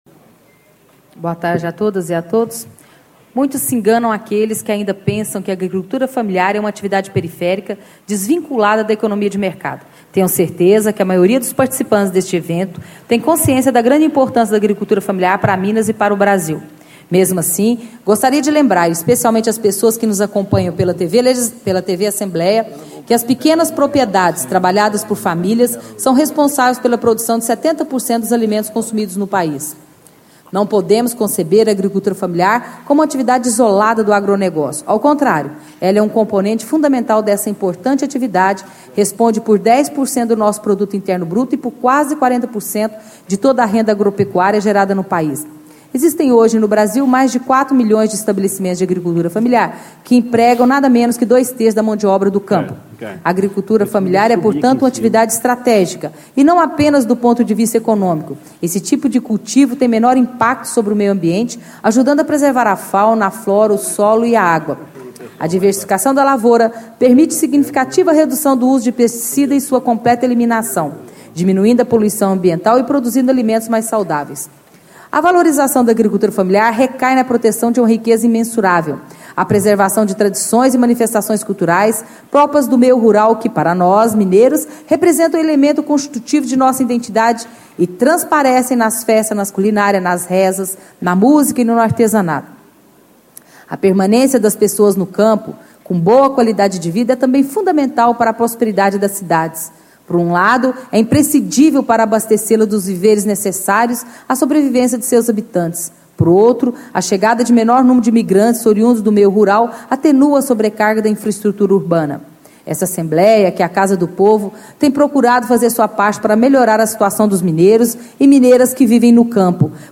Deputada Liza Prado (PSB) - Leitura do Discurso enviado pelo Presidente da Assembleia Legislativa de Minas Gerais, Deputado Dinis Pinheiro (PSDB)